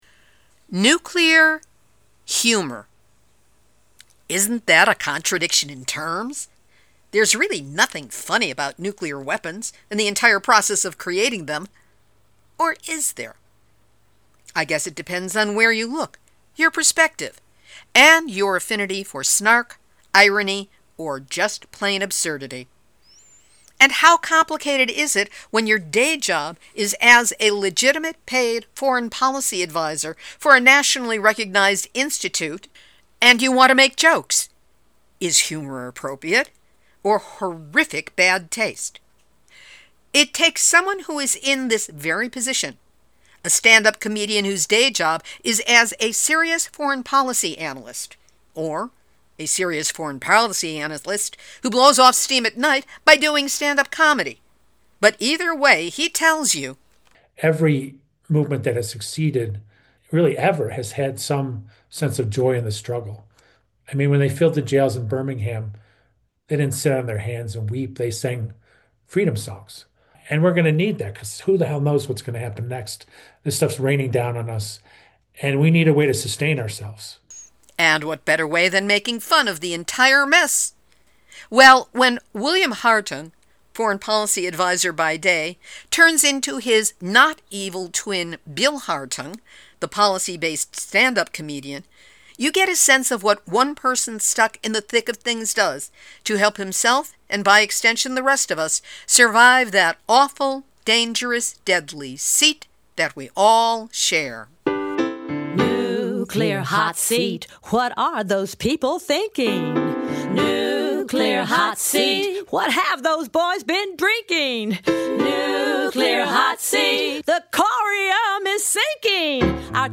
Stand-up comedy